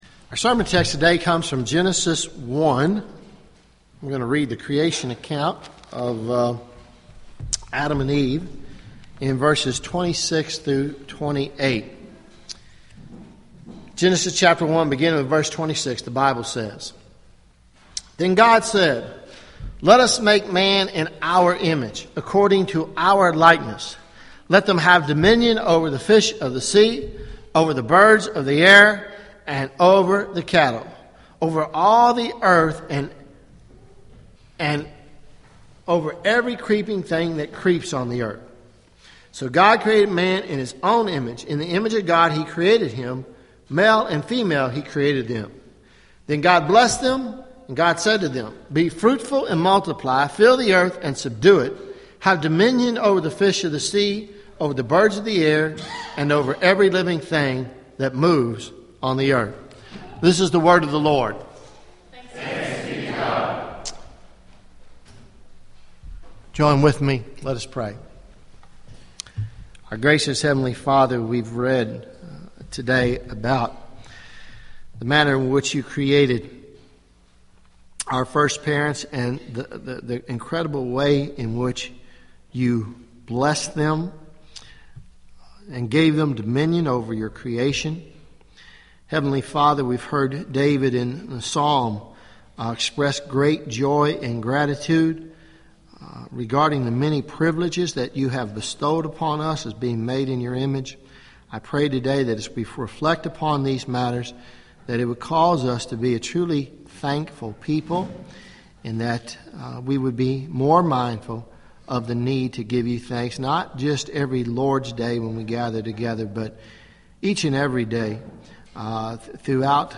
Sermons Nov 09 2014 “Thanksgiving